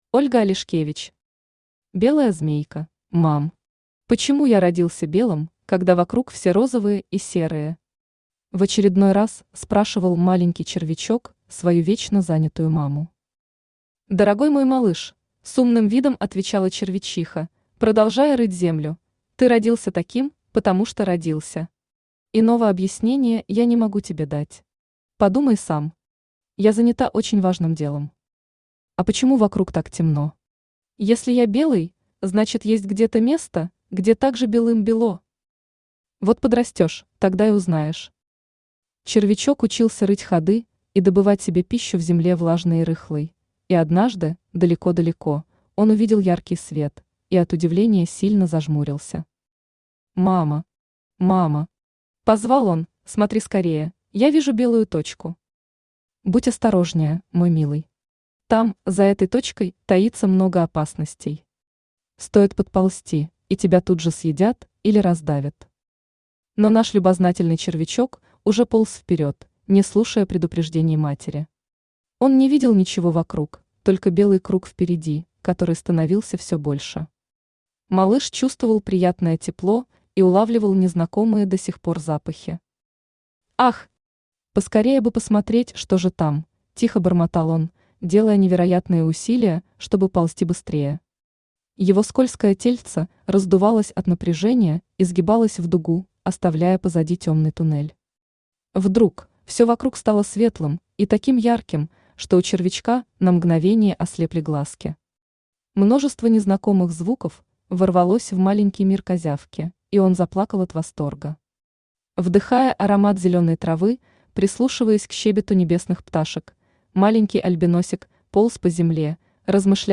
Аудиокнига Белая змейка | Библиотека аудиокниг
Aудиокнига Белая змейка Автор Ольга Алешкевич Читает аудиокнигу Авточтец ЛитРес.